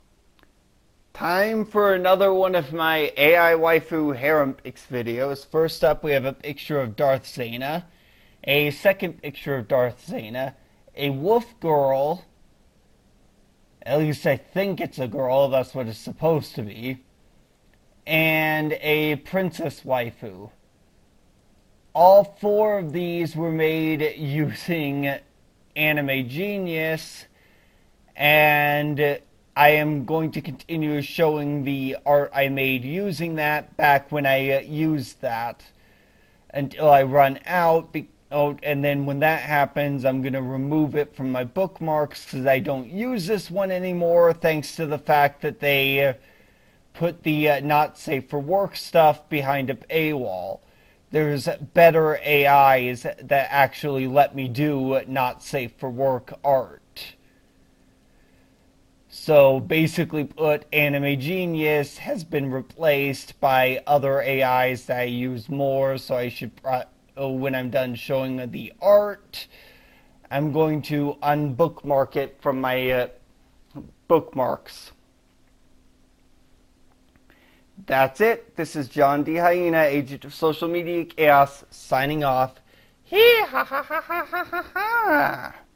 Darth Zannah? The way you say sounds like Darth Zena like Xena Warrior princess just with a Zena and I thought it might be Zannah like Hannah with a Z instead, interesting